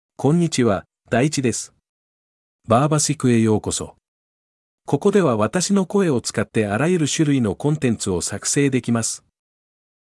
DaichiMale Japanese AI voice
Daichi is a male AI voice for Japanese (Japan).
Voice sample
Listen to Daichi's male Japanese voice.
Male
Daichi delivers clear pronunciation with authentic Japan Japanese intonation, making your content sound professionally produced.